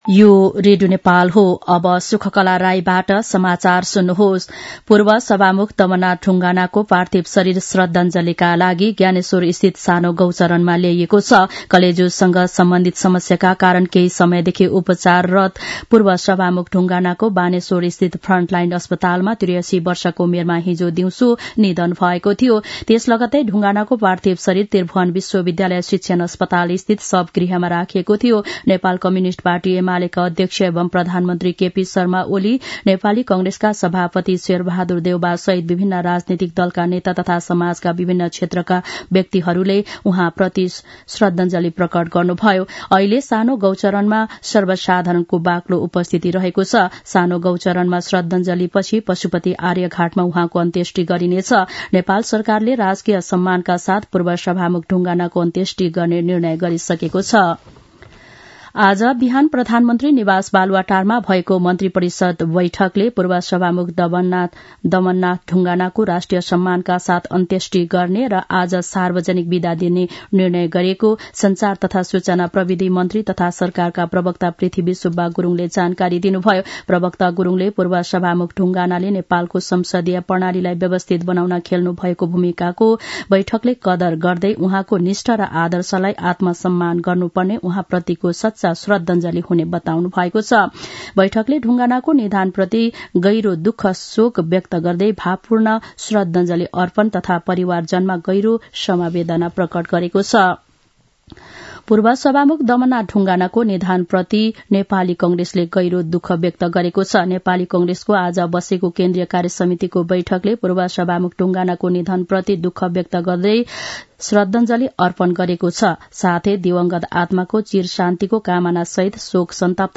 दिउँसो १ बजेको नेपाली समाचार : ४ मंसिर , २०८१
1-pm-nepali-news-1-2.mp3